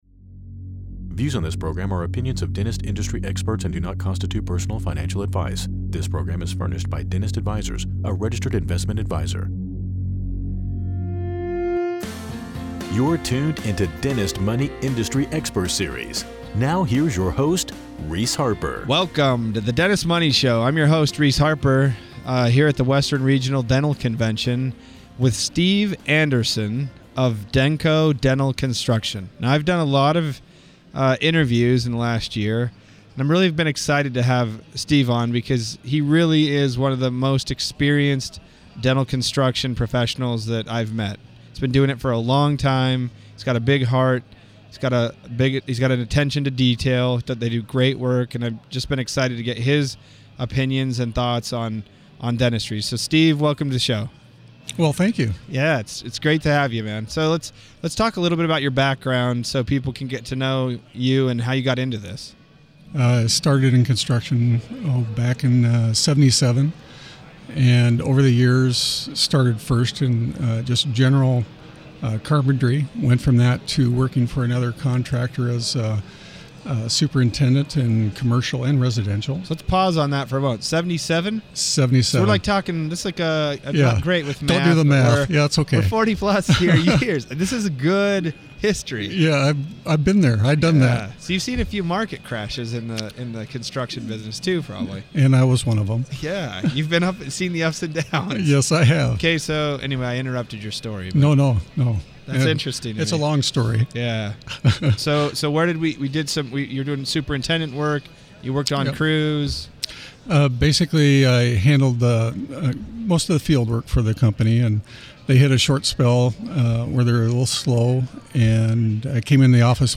Dentist Money™, Industry Expert Series: Interview